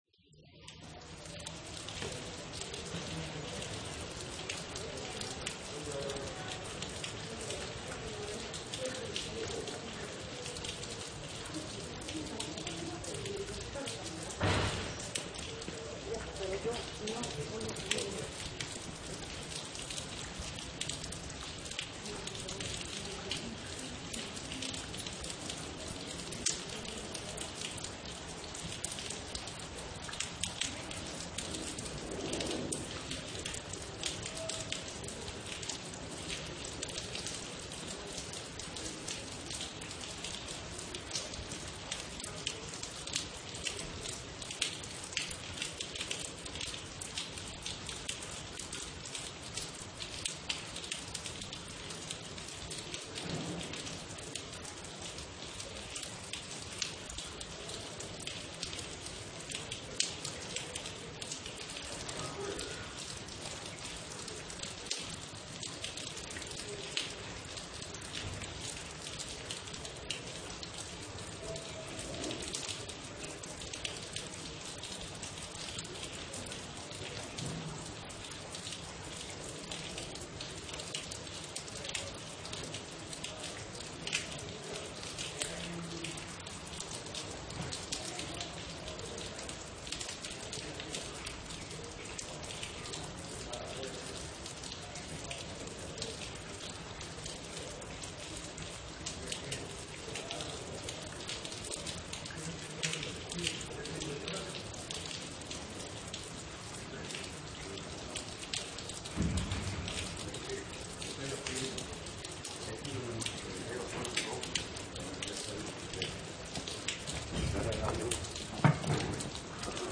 Efectos de sonido
LLUVIA EN UNA CALLE ESTRECHA 1
EFECTO DE SONIDO DE AMBIENTE de LLUVIA EN UNA CALLE ESTRECHA 1
lluvia_en_una_calle_estrecha_1.mp3